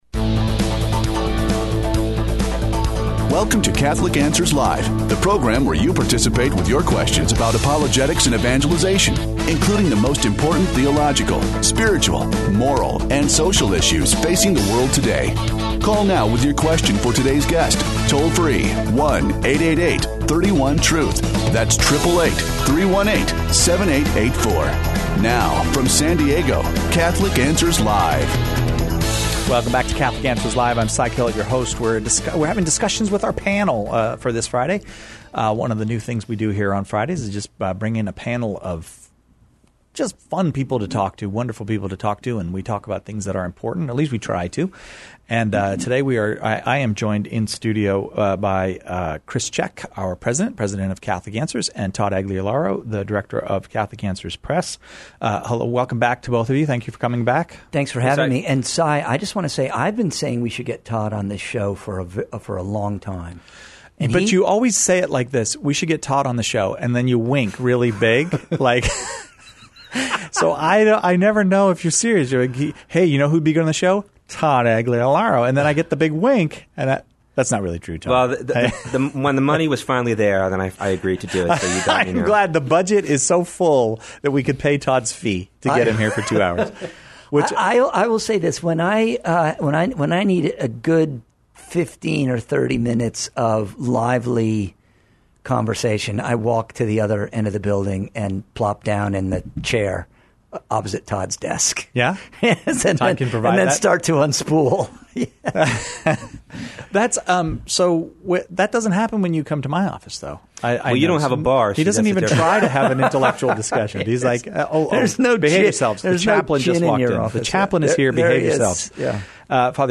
Panel Show